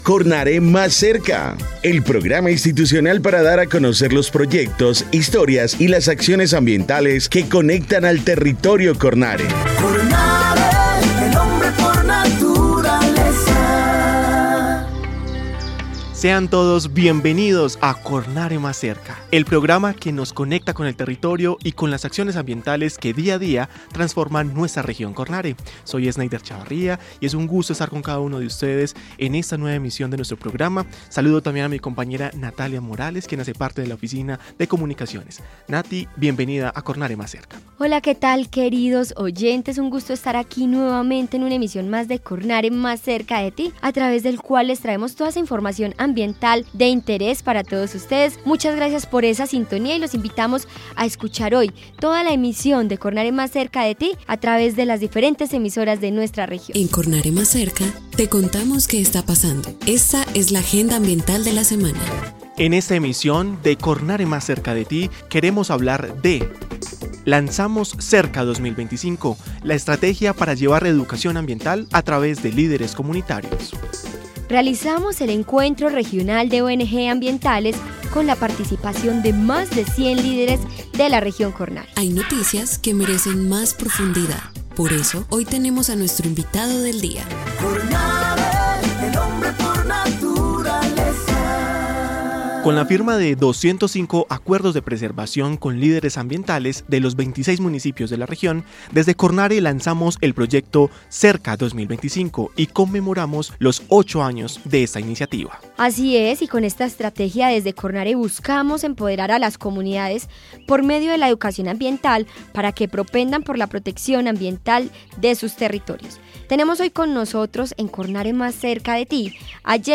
Programa de radio 2025